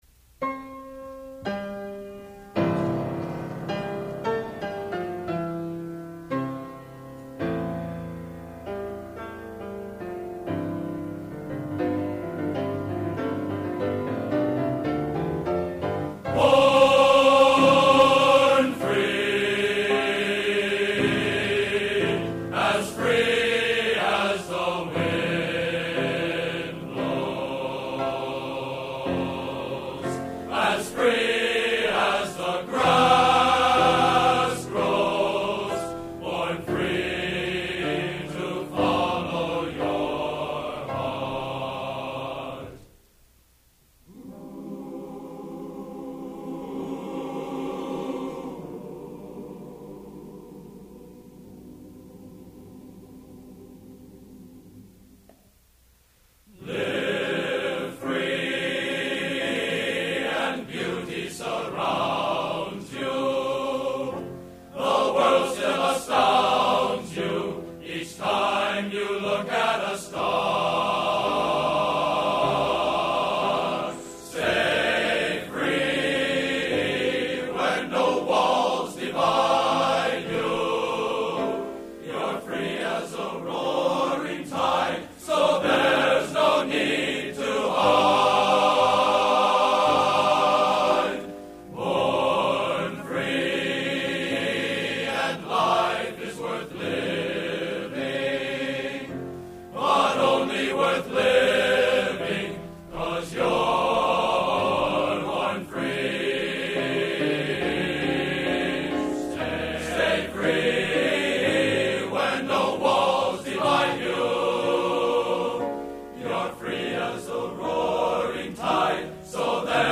Location: West Lafayette, Indiana
Genre: Patriotic | Type: End of Season